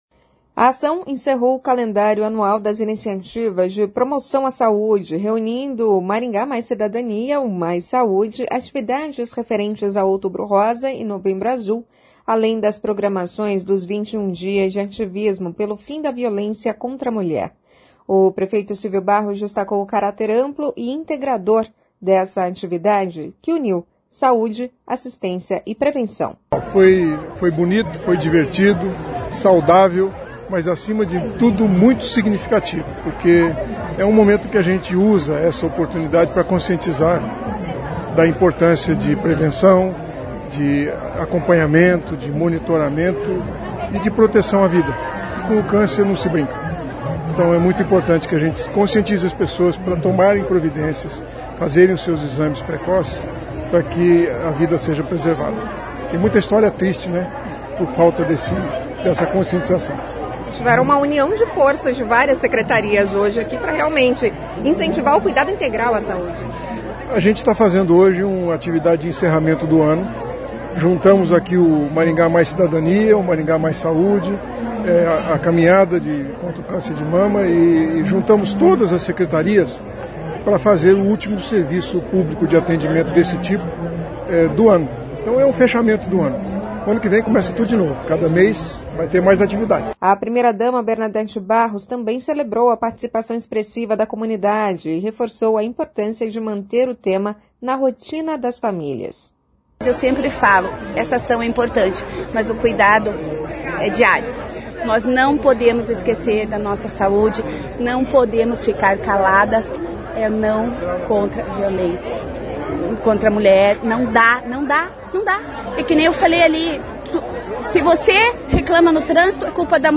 O Parque do Ingá recebeu, na manhã deste domingo (30), uma grande mobilização em defesa da saúde e dos direitos das mulheres.